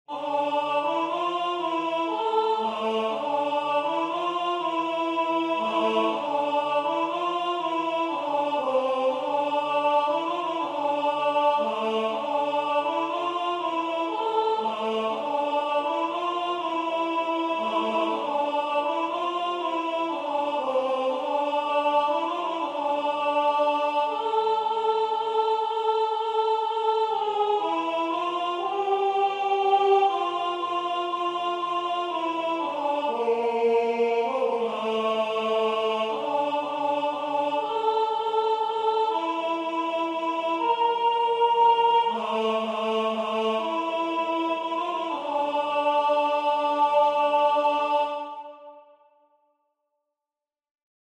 version voix synth.